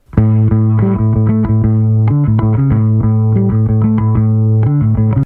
Звуки бас-гитары
Глубокий гул, четкие слэповые партии, плавные джазовые линии — все для ваших проектов или тренировок.